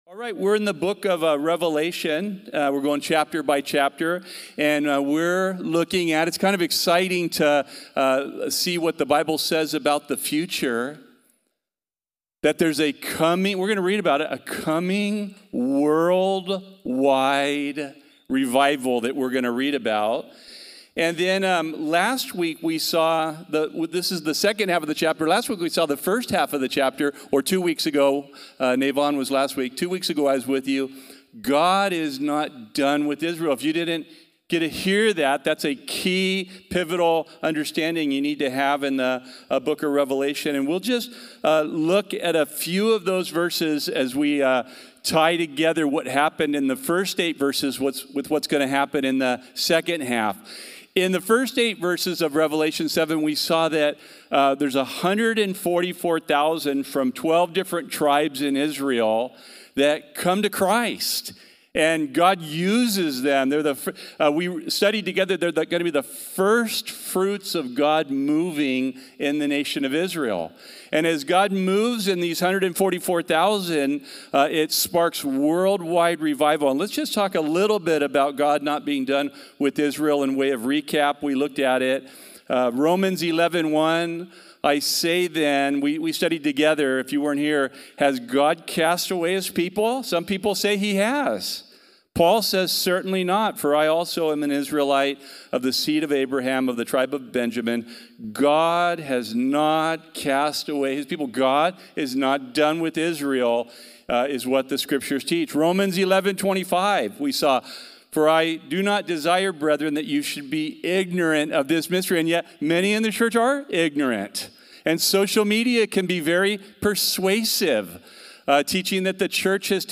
The Bible study given at Calvary Chapel Corvallis on Sunday, February 22, 2026..